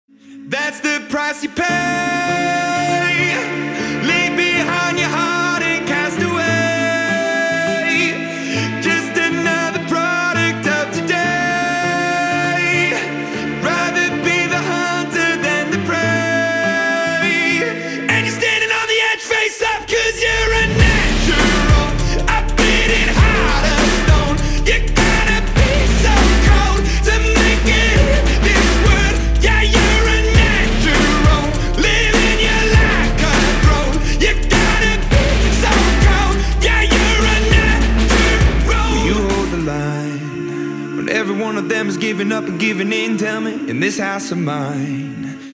• Качество: 320, Stereo
ритмичные
громкие
красивая мелодия
нарастающие
энергичные
alternative
indie rock